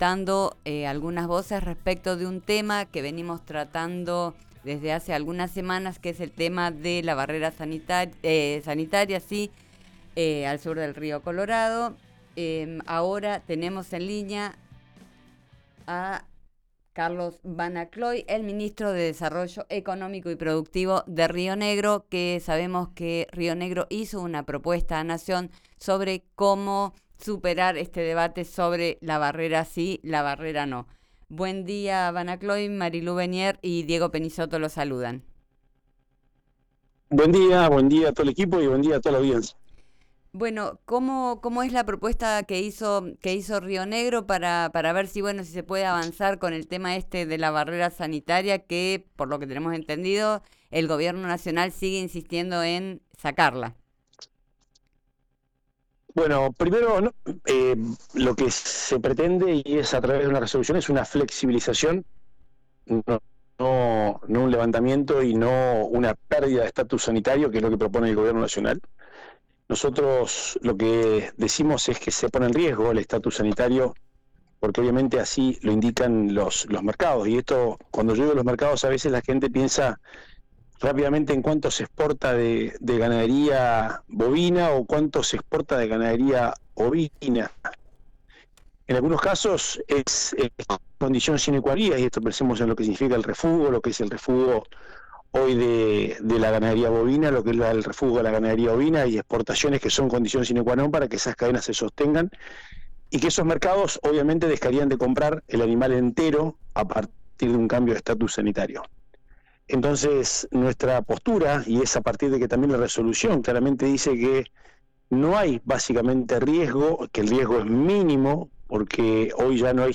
En diálogo con Diario RÍO NEGRO , Banacloy destacó dos aspectos importantes del primer encuentro y es la representación de todas las entidades rurales del país (Sociedad Rural, Confederaciones Rurales, Federación Agraria) “ porque representan intereses de un lado y otro del río Colorado, tienen a los damnificados y a los que buscan ingresar la carne con hueso a la Patagonia ”; y también valoró la apertura del gobierno nacional a la revisión de la medida.